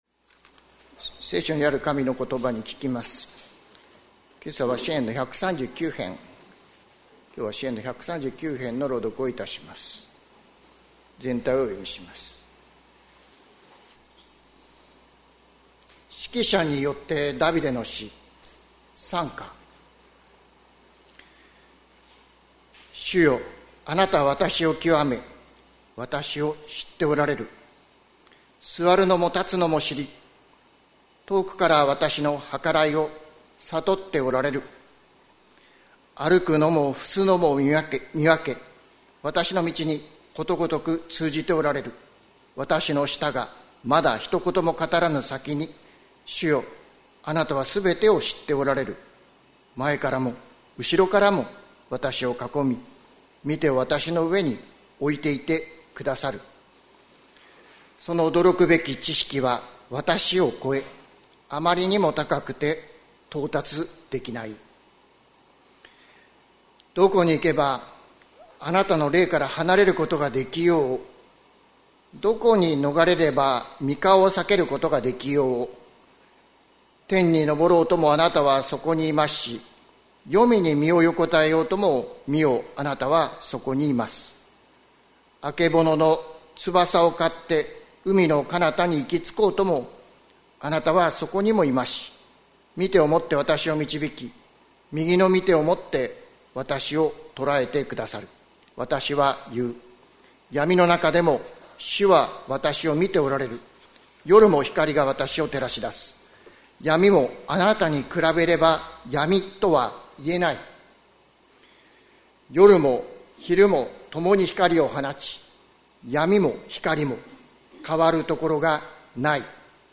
2024年12月29日朝の礼拝「数えてみよ、主の恵み」関キリスト教会
説教アーカイブ。